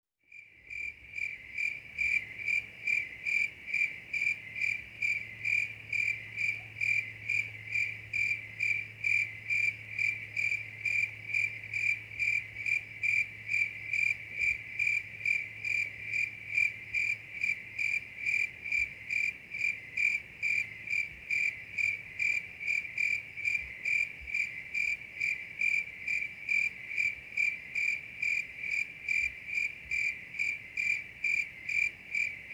Здесь вы найдете стрекотание в разных тональностях, от одиночных особей до хора насекомых.
Ночной стрекот кузнечика или сверчка